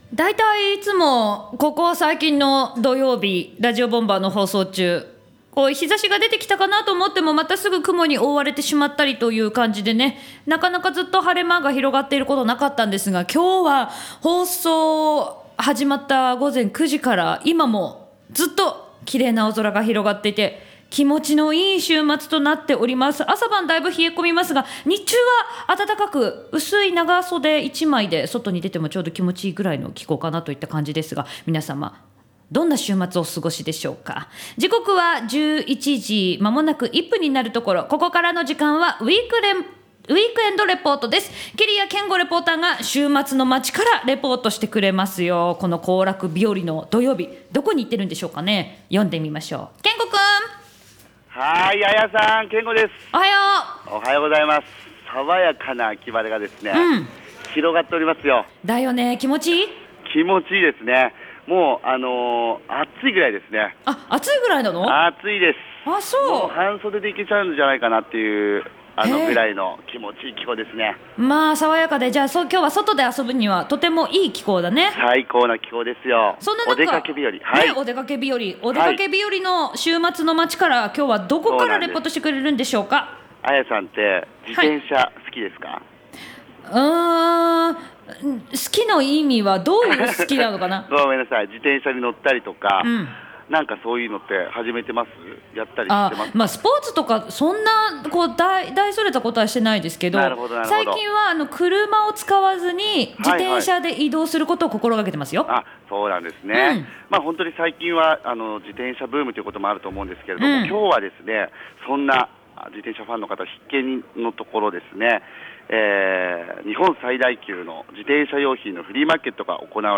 さあ、今日は調布の京王閣競輪場（調布市多摩川）で行われた日本最大級の自転車用品のフリーマーケットをご紹介しました！